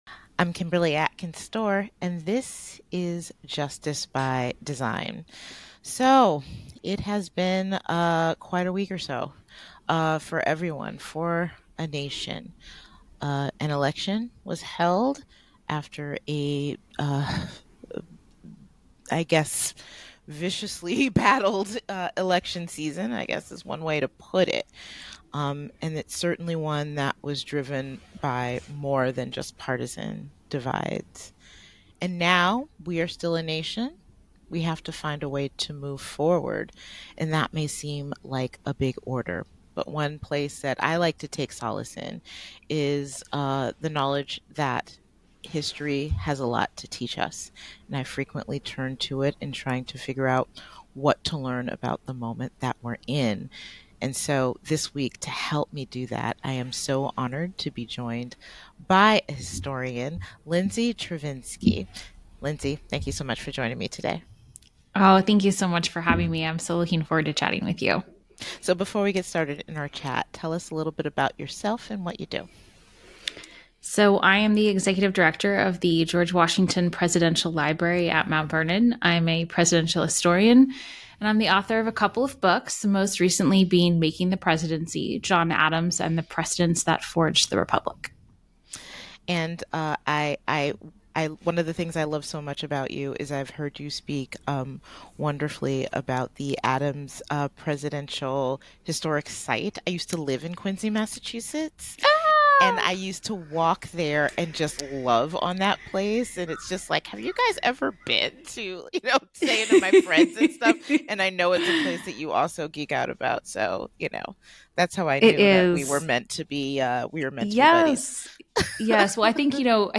The conversation highlights the need for civic virtue among leaders and the responsibility of citizens to engage critically with information.